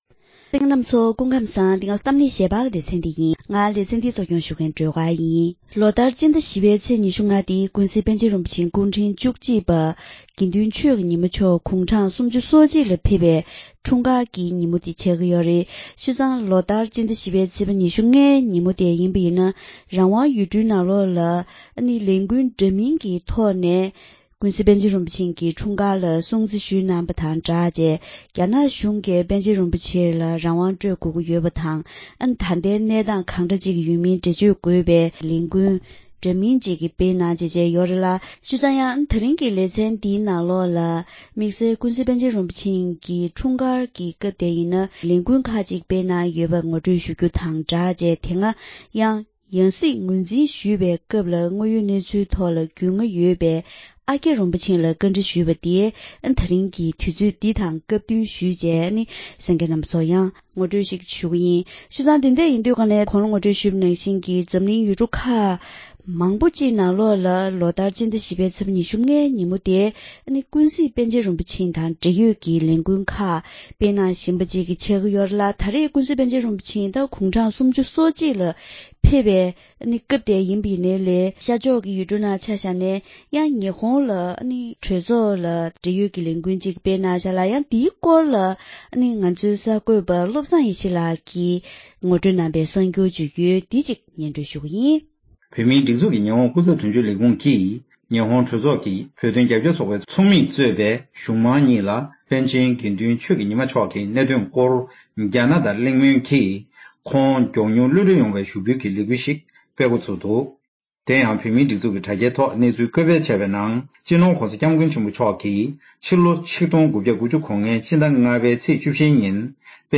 ད་རིང་གི་གཏམ་གླེང་ཞལ་པར་ལེ་ཚན་ནང་ལོ་ལྟར་སྤྱི་ཟླ་༤ཚེས་༢༥ཉིན་ཀུན་གཟིགས་པཎ་ཆེན་རིན་པོ་ཆེ་སྐུ་ཕྲེང་བཅུ་གཅིག་པའི་སྐུའི་འཁྲུངས་སྐར་གྱི་ཉིན་མོ་དེར་རང་དབང་ཡུལ་གྲུའི་ནང་རྣམ་པ་འདྲ་མིན་ཐོག་ནས་སྲུང་བརྩི་ཞུ་བཞིན་ཡོད་པ་ལྟར་འདི་ལོ་ཡང་དམིགས་བསལ་དྲ་རྒྱ་བརྒྱུད་ལས་འགུལ་ཁག་སྤེལ་མུས་ཡིན་པའི་སྐོར་དང་། དེ་སྔ་ཡང་སྲིད་ངོས་འཛིན་ཞུས་པའི་སྐབས་རྒྱ་ནག་གཞུང་གིས་གསང་བ་དང་བྱ་ཐབས་འདྲ་མིན་ལག་བསྟར་བྱས་ཡོད་པའི་སྐོར་ལ་ཨ་ཀྱཱ་རིན་པོ་ཆེས་མཁྱེན་རྟོགས་ཡོད་པའི་དངོས་ཡོད་གནས་ཚུལ་ངོ་སྤྲོད་གནང་པ་ཞིག་གསན་རོགས་གནང་།